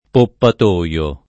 poppatoio [ poppat 1L o ] s. m.; pl. ‑toi